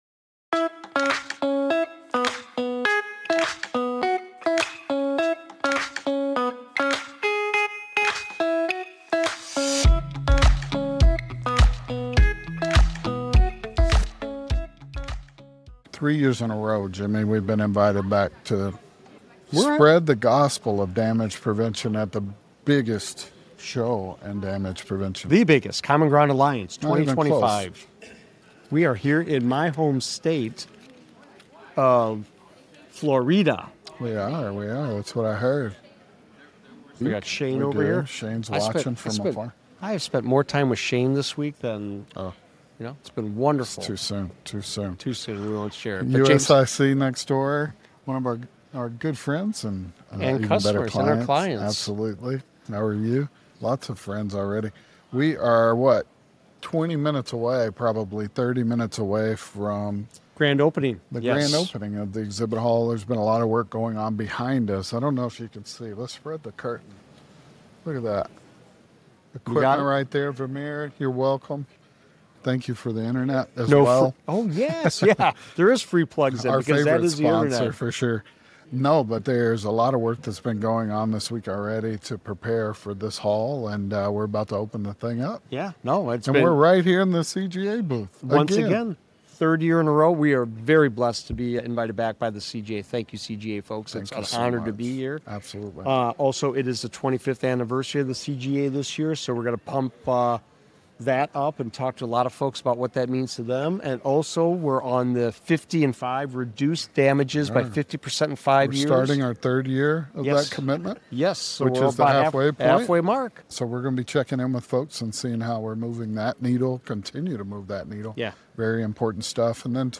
Live @ Common Ground Alliance Annual Conference